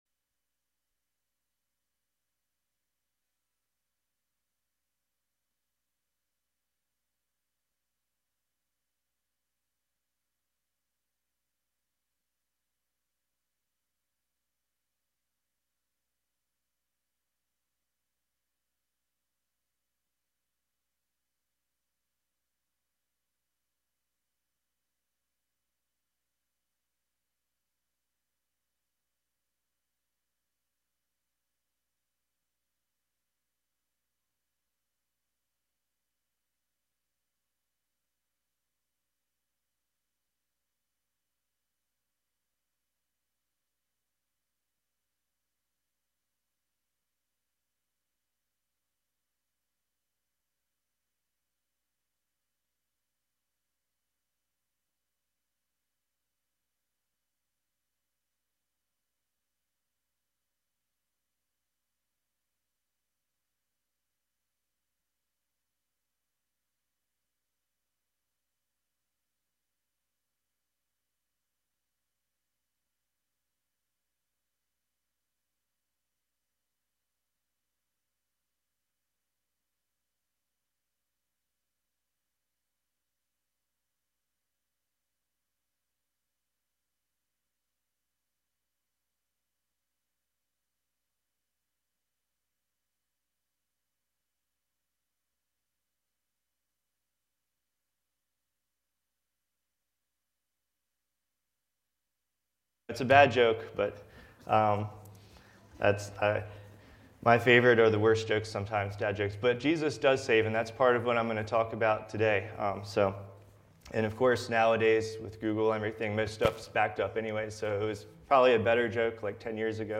Series: Guest Speaker
Matthew 5.1-11 Service Type: Sunday Worship Service Download Files Bulletin « Paul